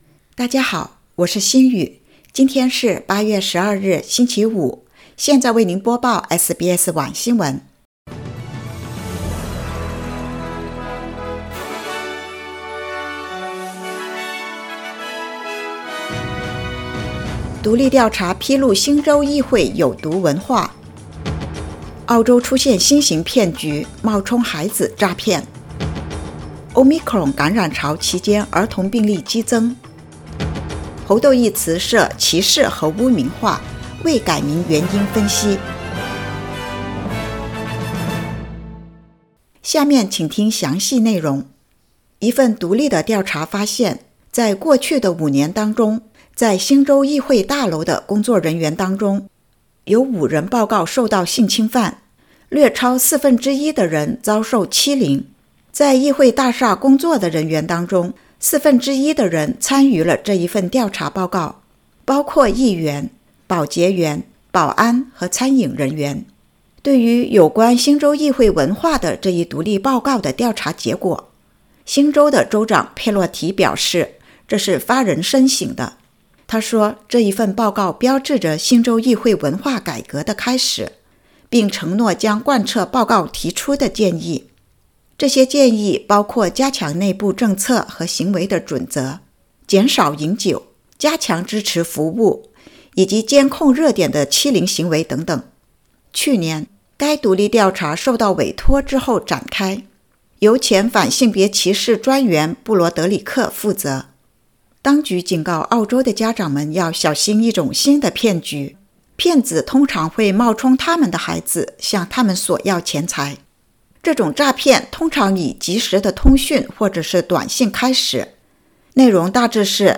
SBS Mandarin evening news Source: Getty / Getty Images